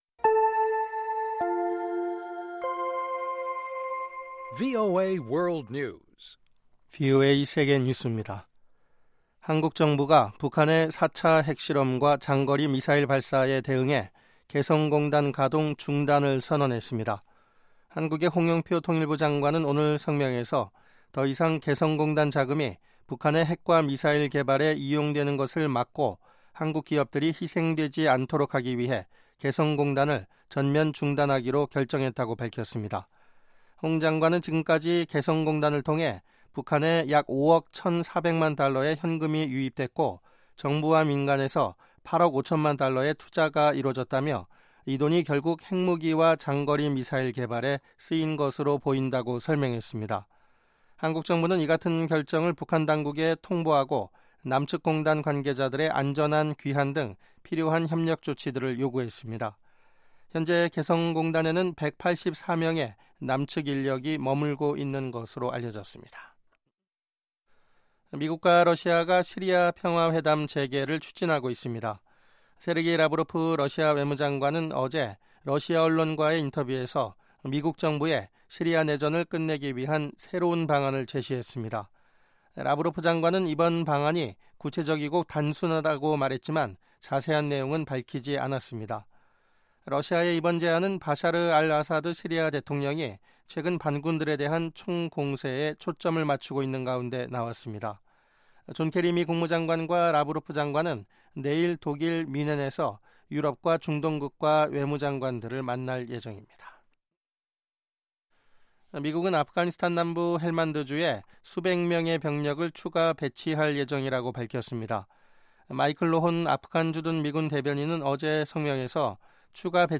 VOA 한국어 방송의 간판 뉴스 프로그램 '뉴스 투데이' 2부입니다.